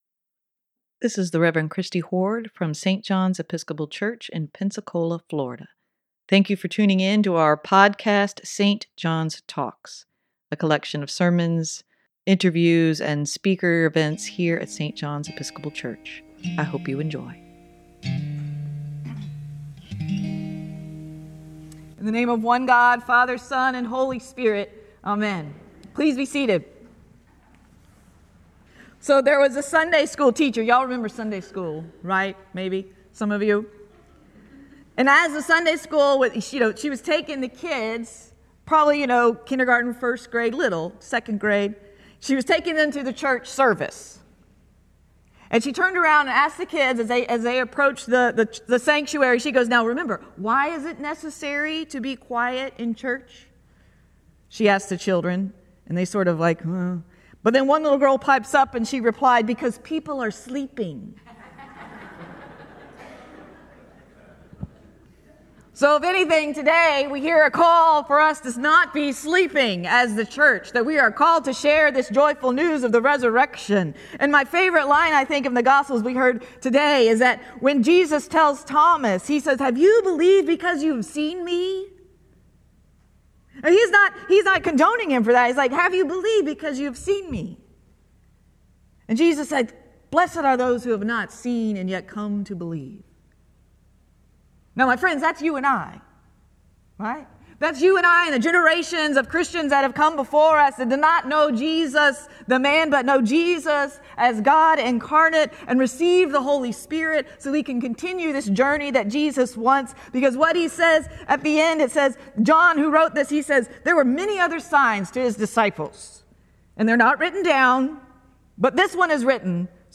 Sermon for April 7, 2024: We are called to walk in the Light